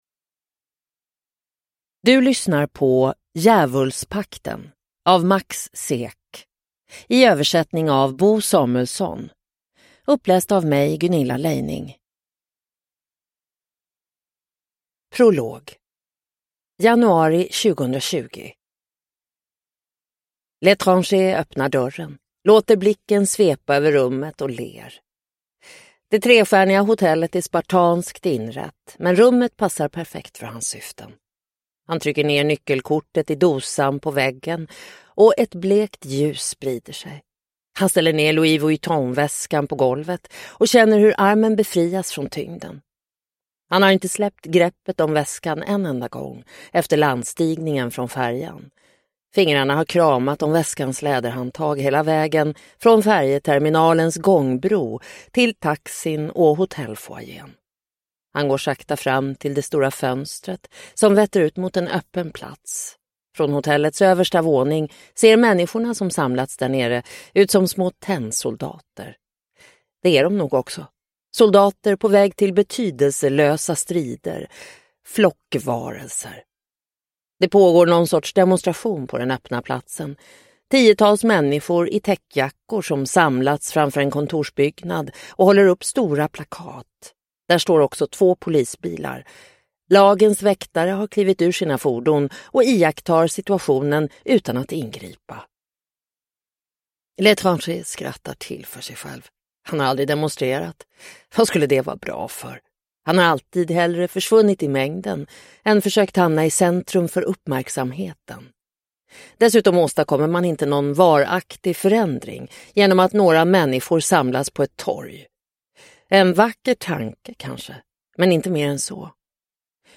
Djävulspakten – Ljudbok – Laddas ner